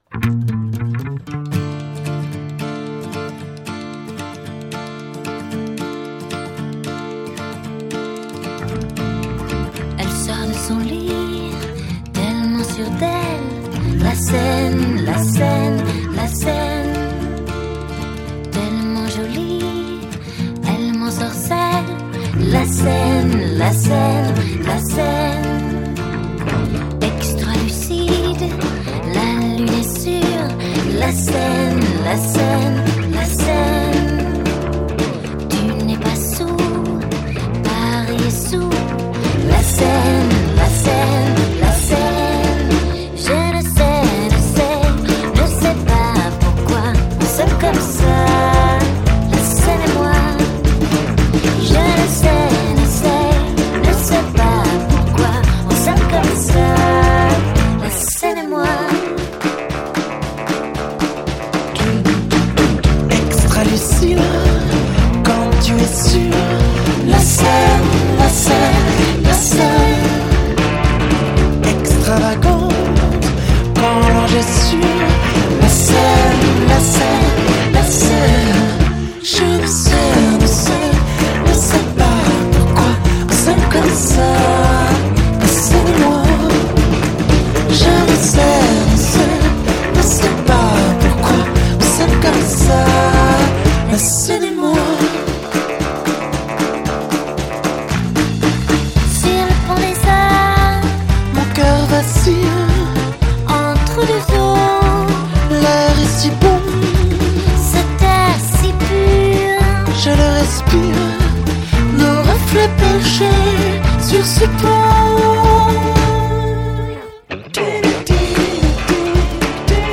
Жанр: Musique de films